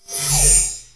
inventory_off.wav